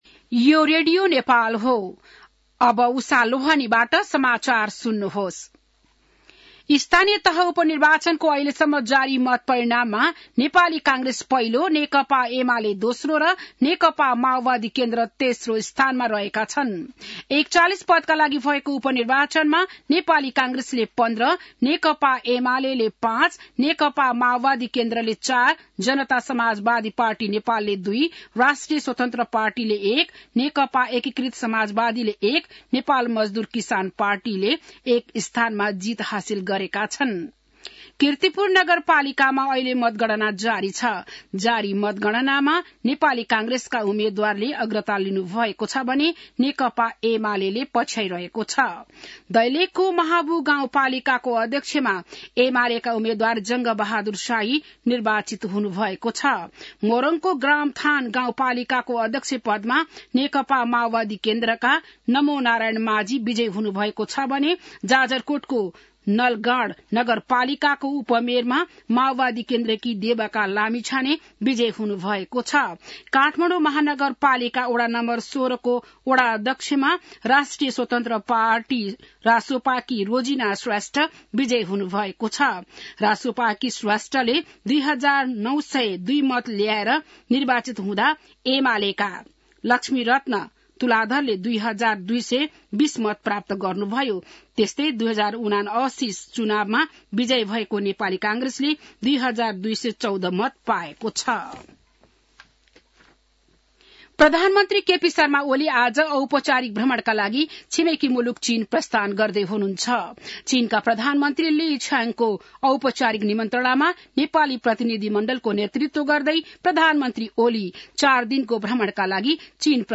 बिहान १० बजेको नेपाली समाचार : १८ मंसिर , २०८१